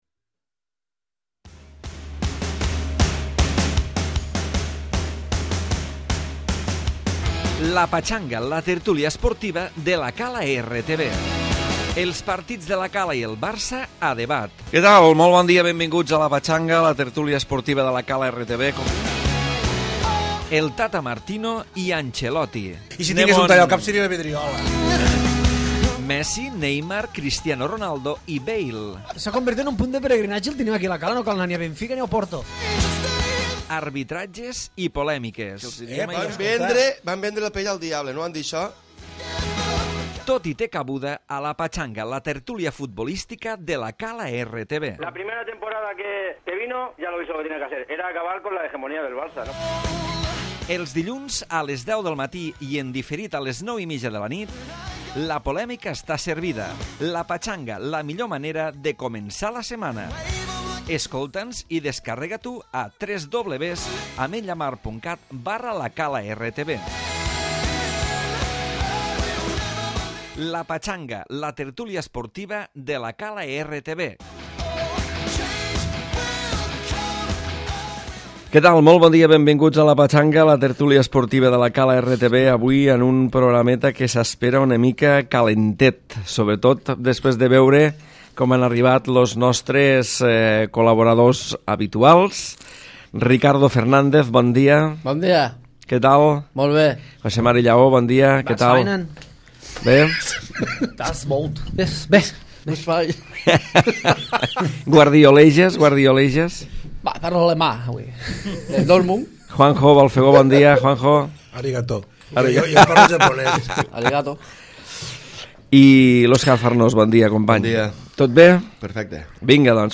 Interessant debat el generat avui entre els convidats sobre la prohibició de la FIFA al Barça de fer cap fitxatge fins l'any que be.